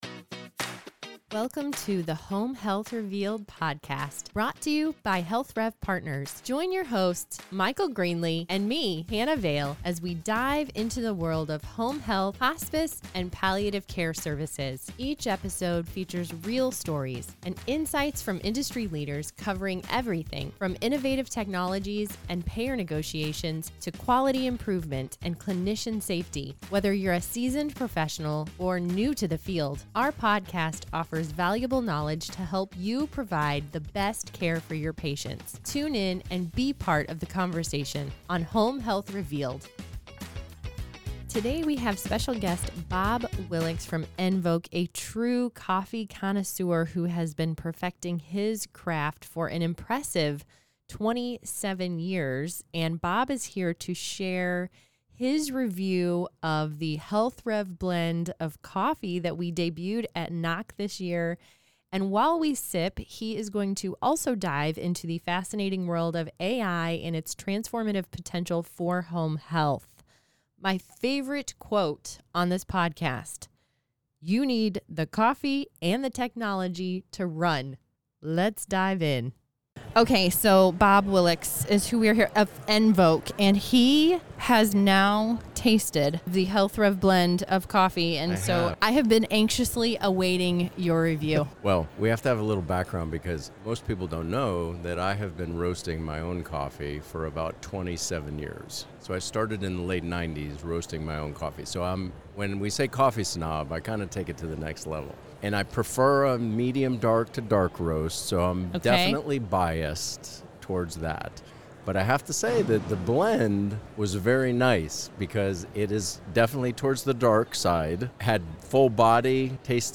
sip their coffee at the NAHC annual conference